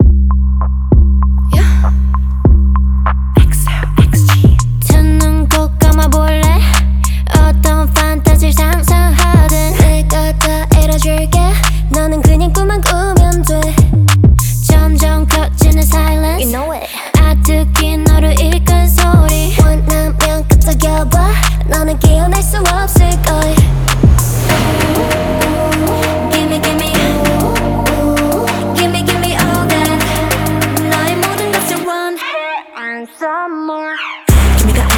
K-Pop
2025-08-25 Жанр: Поп музыка Длительность